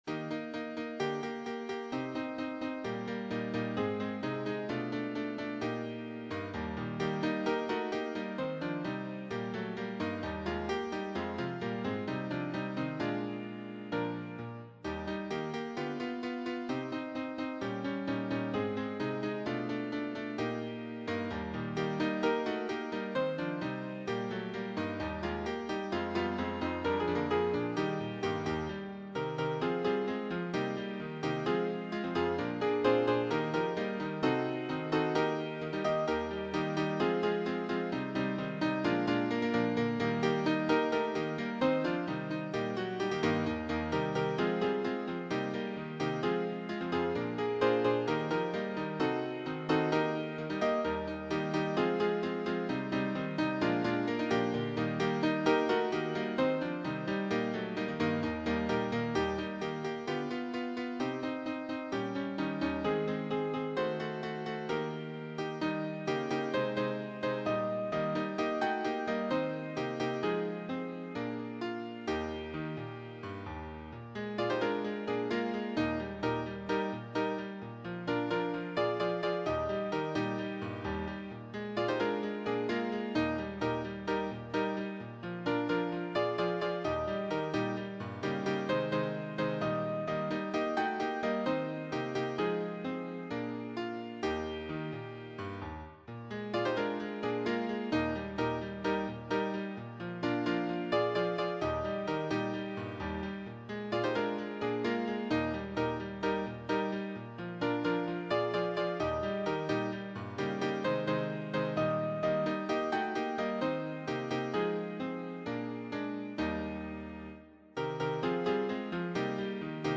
choir SAT(AT)B